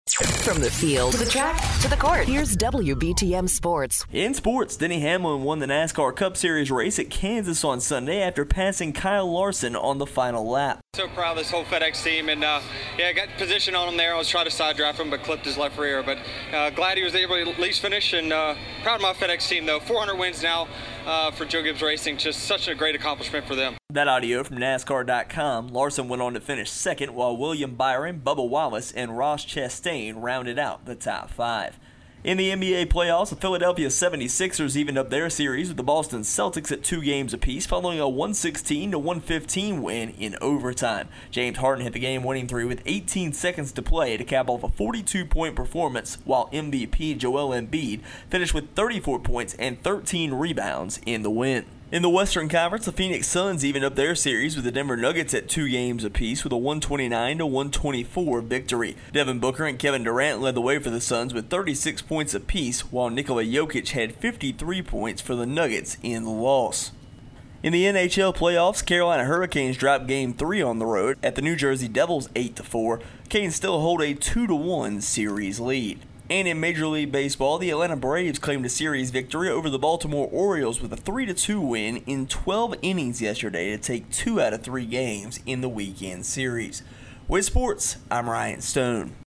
Hamlin Wins at Kansas, NBA Playoffs, and More in Our Local Sports Report